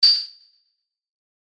キン
/ F｜演出・アニメ・心理 / F-80 ｜other 再構成用素材